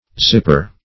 zipper \zip"per\, n.